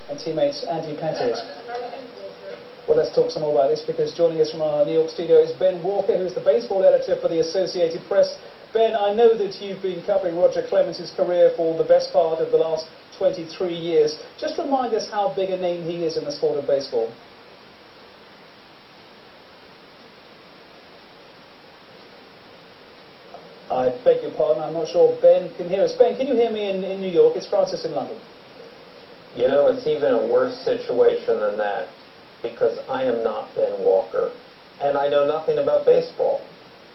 Tags: News blooper news news anchor blooper bloopers news fail news fails broadcaster fail